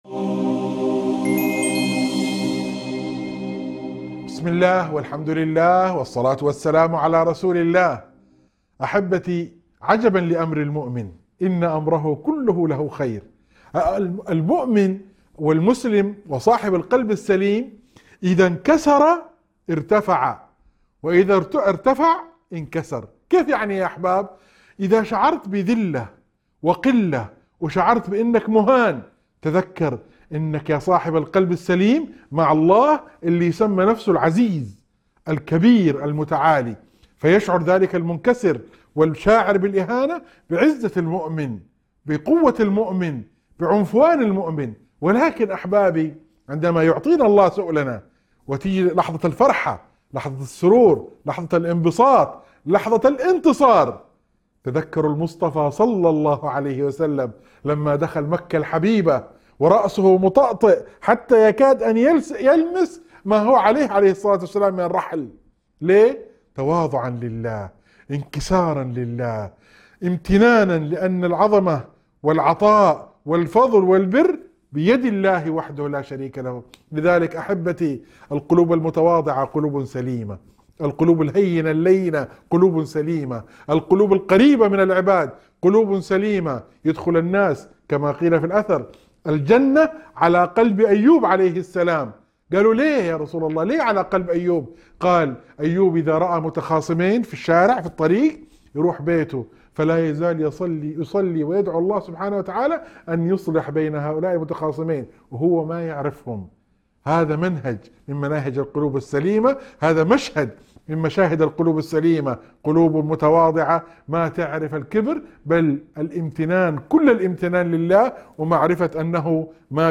موعظة مؤثرة تُذكّر المؤمن بعزته مع الله حتى في لحظات الضعف والذل الظاهري. تستحضر صفات القلب السليم والمتواضع، وتستشهد بسيرة النبي صلى الله عليه وسلم وأثر التواضع، لتعزيز الصبر والرضا والأنس بالله تعالى.